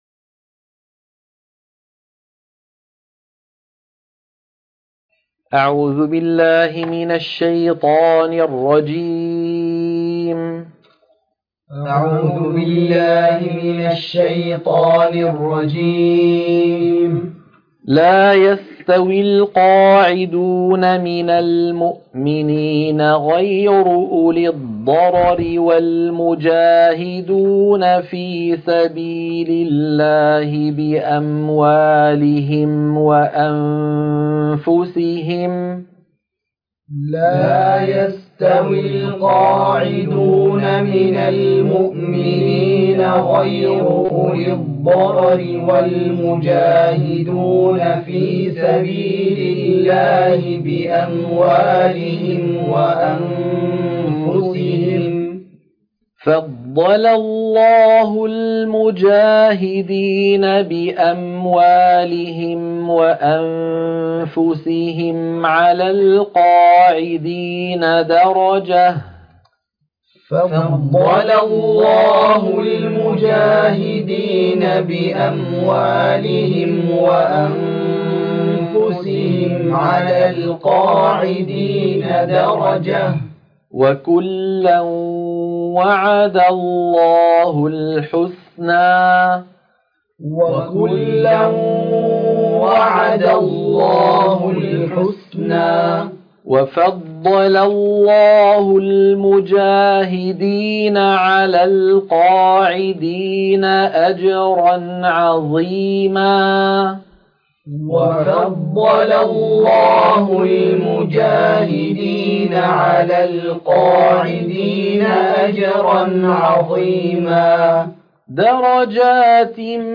عنوان المادة تلقين سورة النساء - الصفحة 94 التلاوة المنهجية